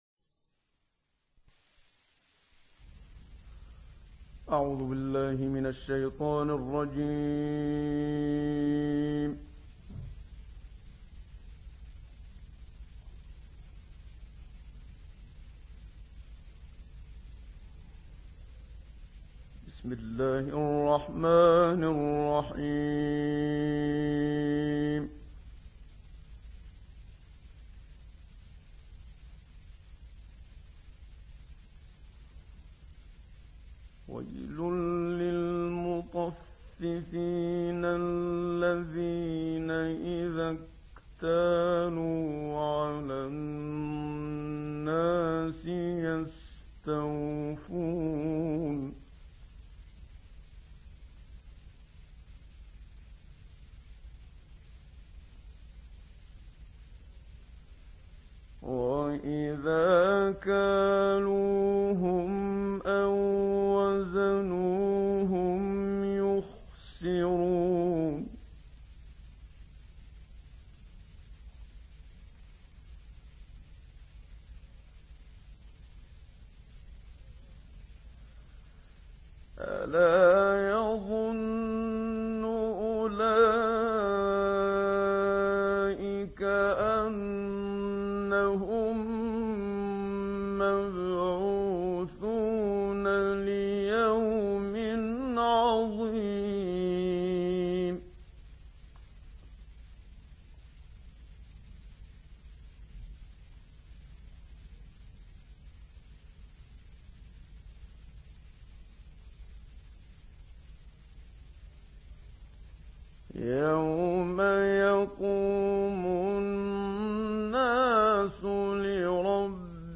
تلاوت استودیویی سوره مطففین «محمد صدیق منشاوی»
گروه شبکه اجتماعی: سوره مطففین، انشقاق و بروج را با صدای محمد صدیق منشاوی می‌شنوید.